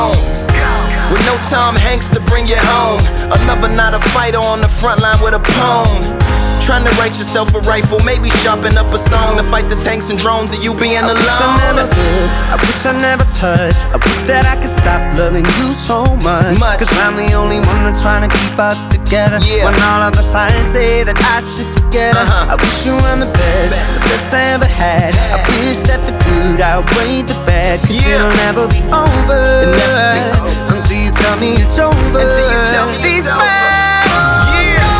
Pop Rock
Жанр: Поп музыка / Рок